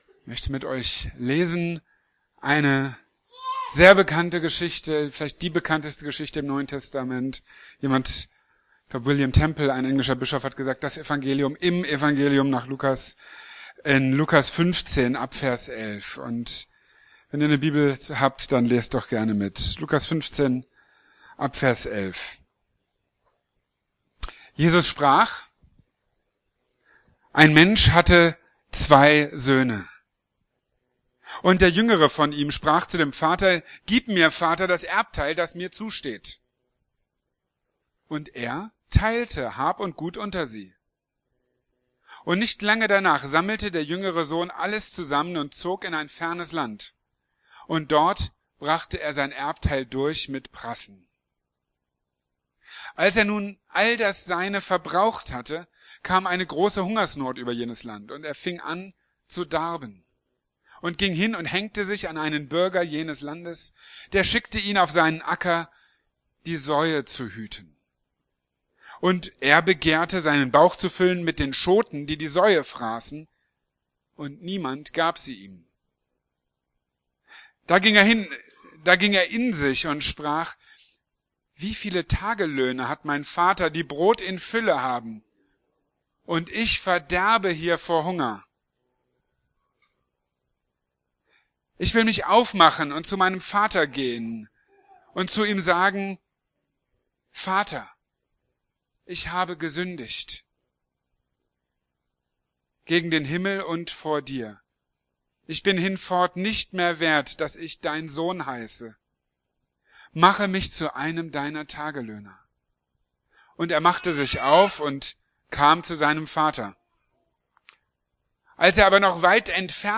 | Marburger Predigten